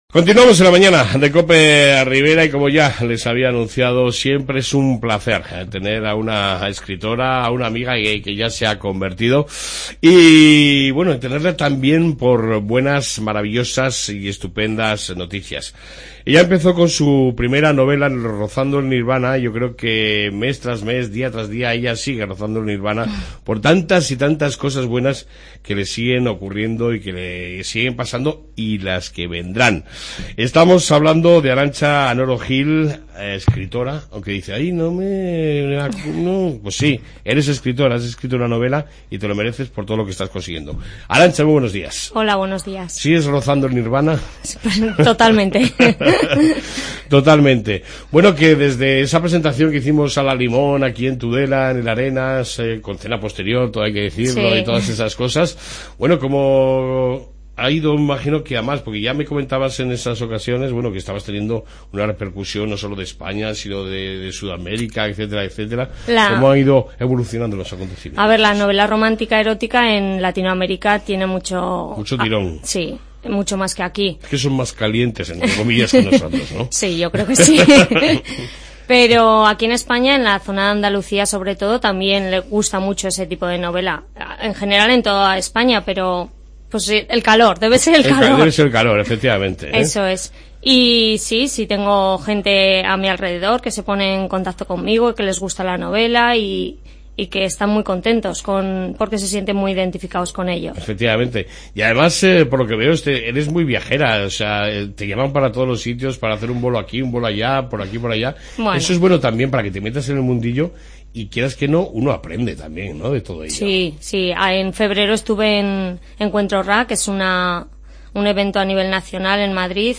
Entrevista con la Escritora Tudelana